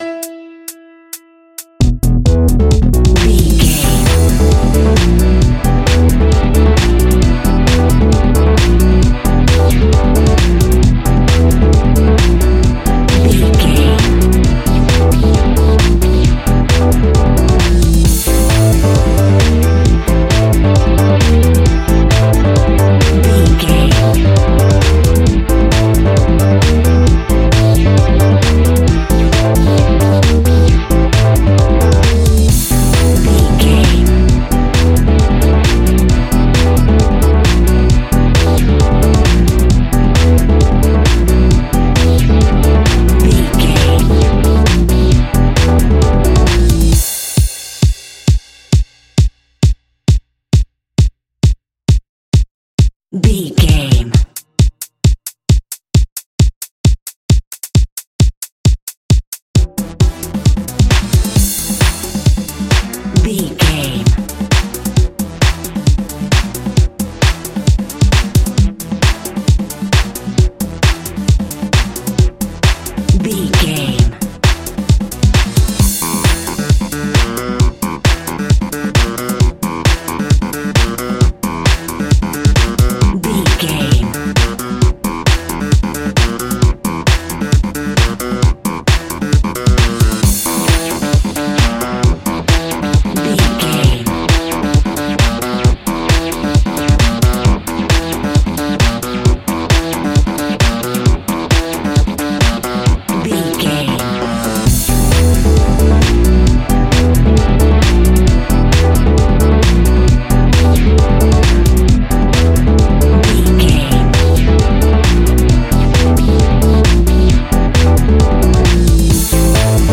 Aeolian/Minor
groovy
futuristic
uplifting
drums
electric organ
electro house
funky house instrumentals
synth bass
synth leads
percussion
guitar